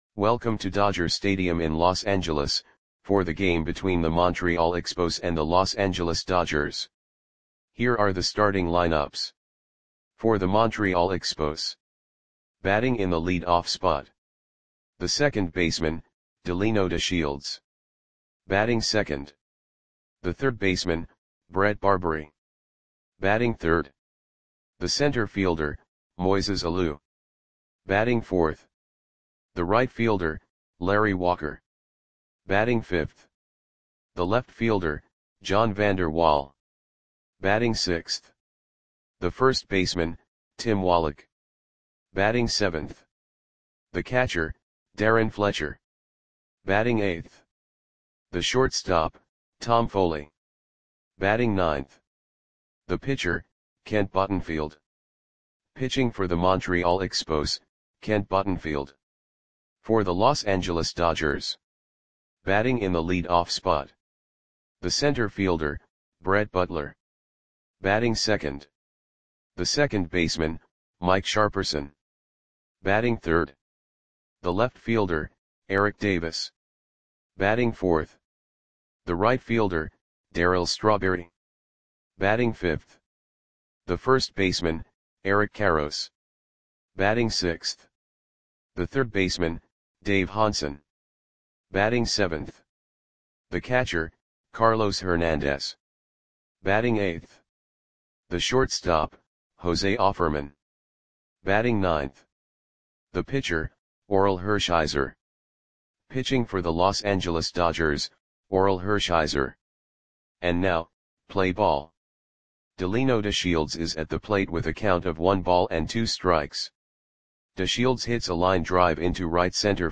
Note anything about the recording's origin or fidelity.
Lineups for the Los Angeles Dodgers versus Montreal Expos baseball game on July 6, 1992 at Dodger Stadium (Los Angeles, CA).